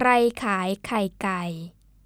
Khrai Khaay+ Khai' Kai' (
This example shows you the difference in the intonation of sell (khaay+ which is a rising tone) and egg (khai' which is low).
In the word egg (khai'), notice the aspirated kh sound versus kai (chicken).